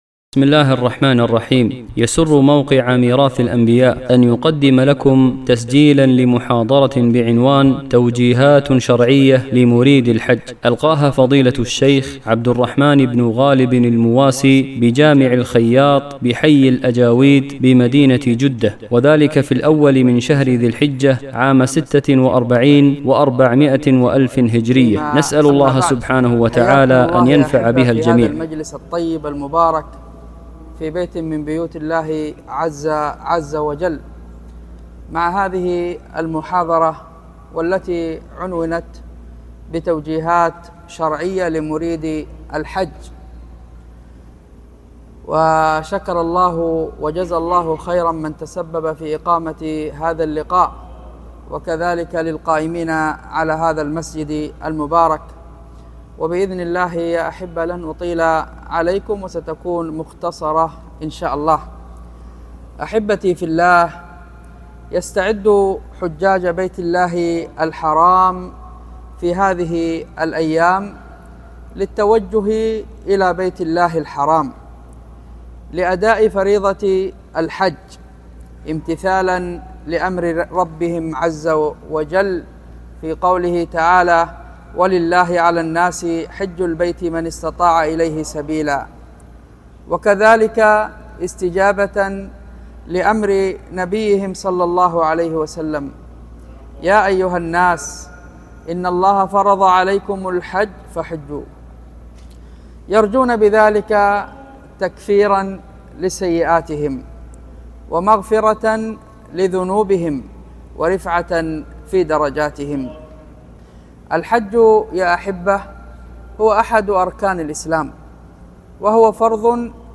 محاضرة
محاضرة-توجيهات-شرعية-لمريد-الحج.mp3